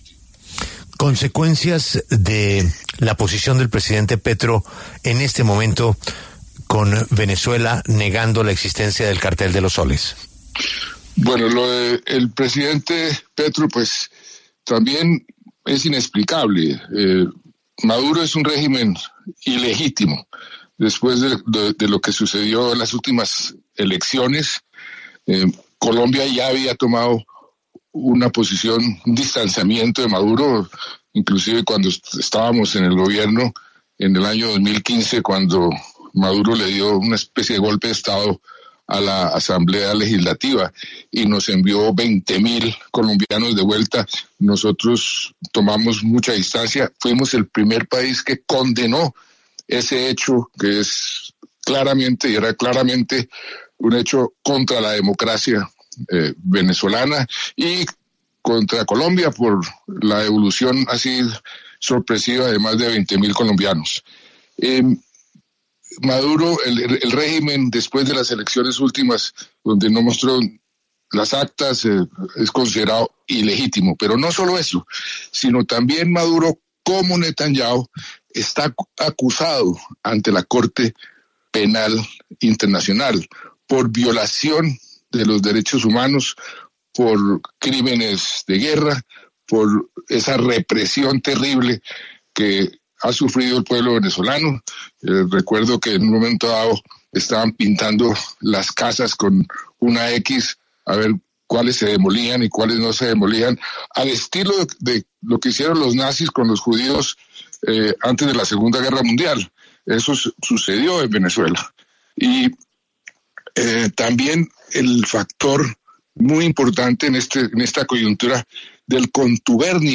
El exmandatario de los colombianos Juan Manuel Santos estuvo en La W, con Julio Sánchez Cristo, para hablar de las palabras del presidente de la República, Gustavo Petro, en las que negó la existencia del Cartel de los Soles.